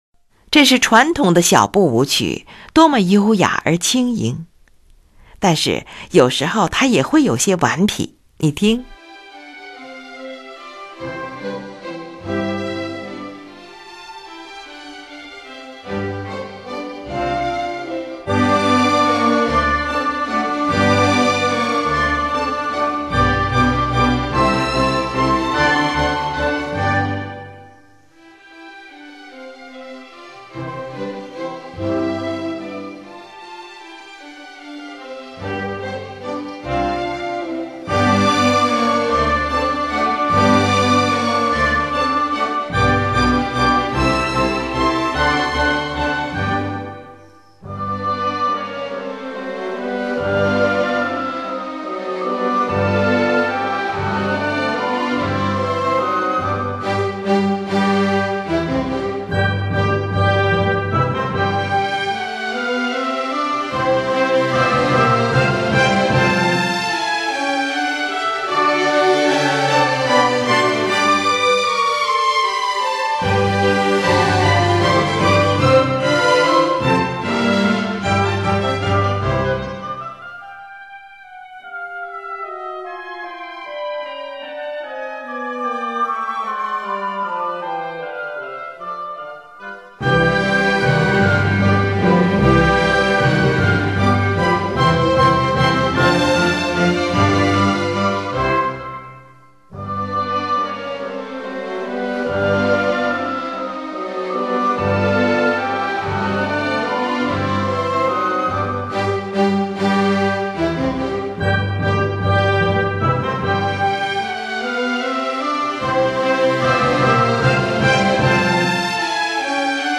第三乐章：这是传统的小步舞曲，多么优雅而轻盈！
第三乐章，一如传统的交响曲的安排——第三乐章是一个传统的小步舞曲。